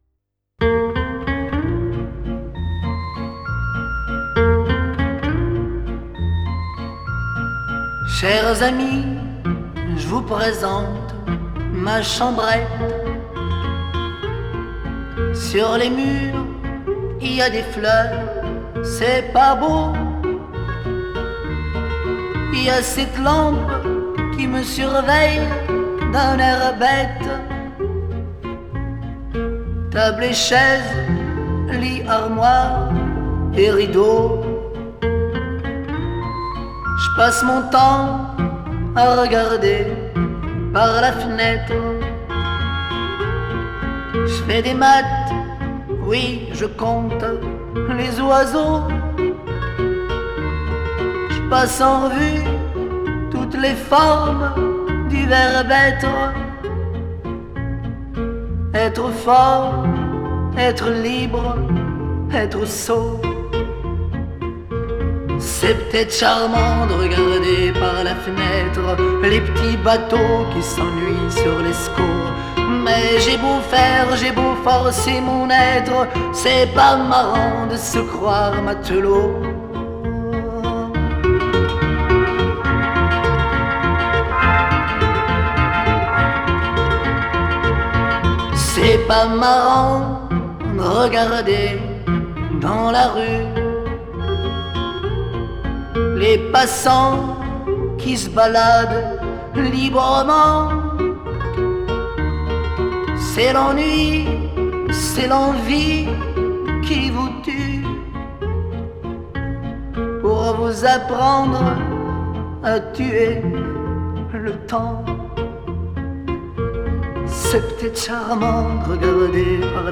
Genre: Chanson, French Pop